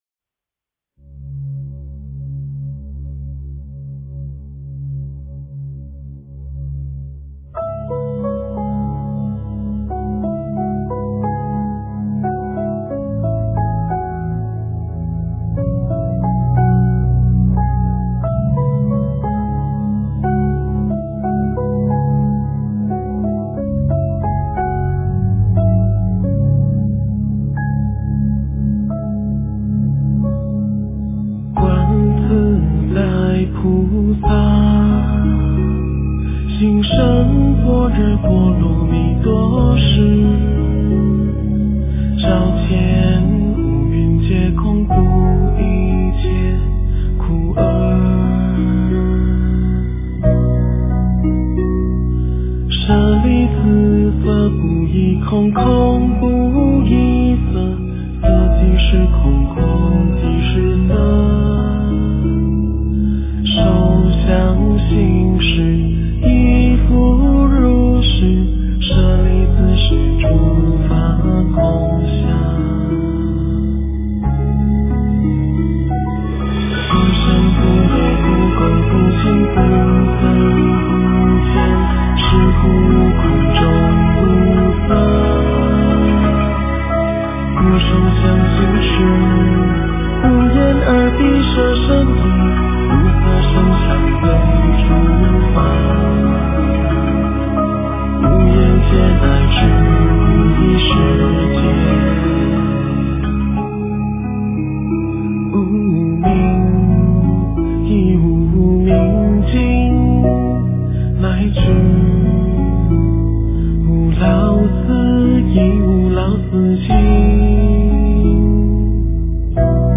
诵经
佛音 诵经 佛教音乐 返回列表 上一篇： 大悲咒-车载版 下一篇： 大悲咒-古梵音 相关文章 南无阿弥陀佛-超长版--东林佛号 南无阿弥陀佛-超长版--东林佛号...